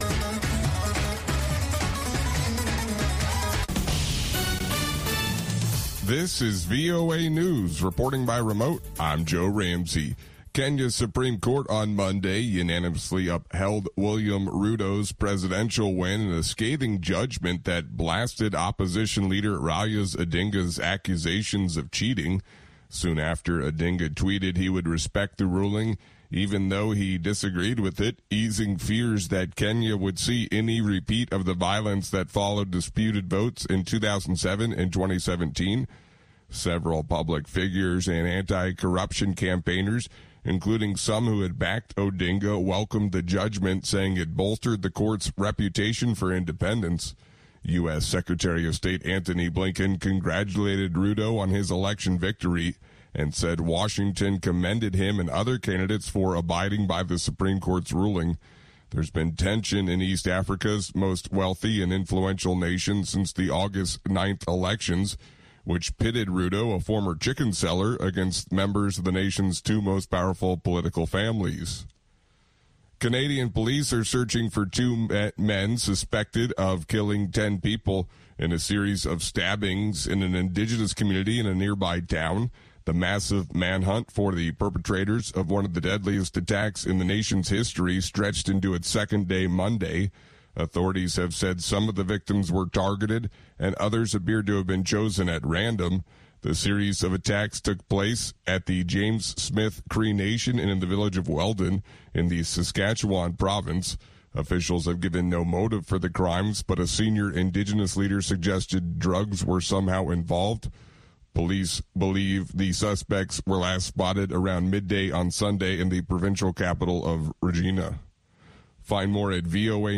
Nûçeyên Cîhanê ji Dengê Amerîka